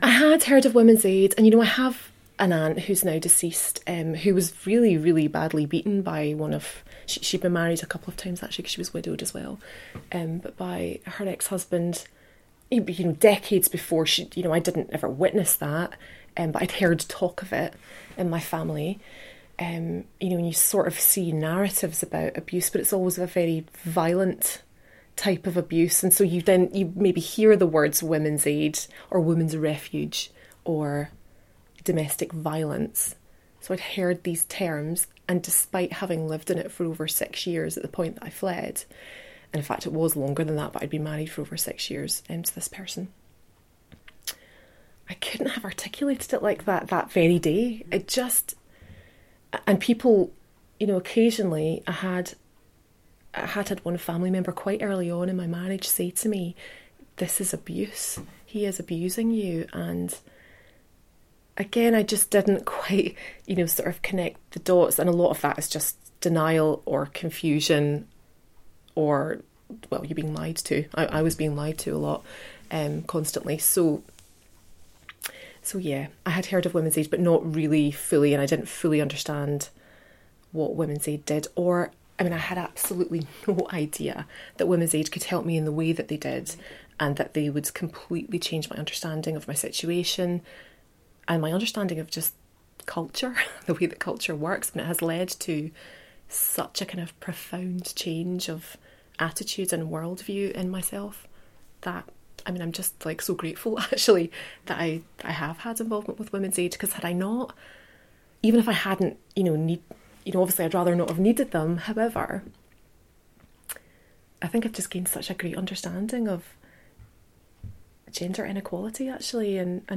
Speaking Out: Oral history clips
The Speaking Out project is gathering oral history interviews with women connected to the Women’s Aid movement, both past and present.